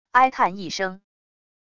哀叹一声wav音频